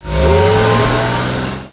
truckout.wav